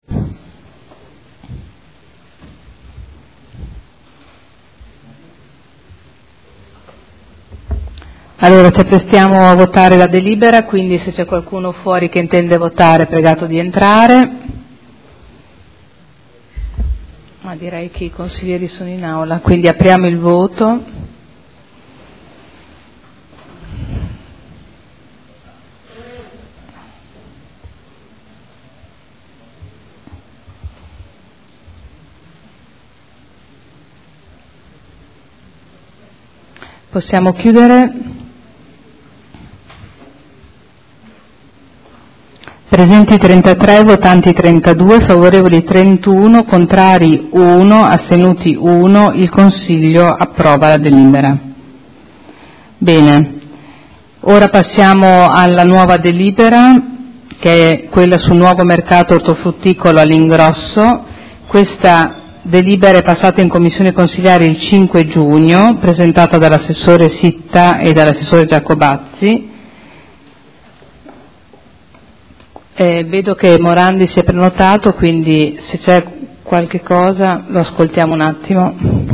Seduta del 18/06/2012. Mette ai voti proposta di deliberazione.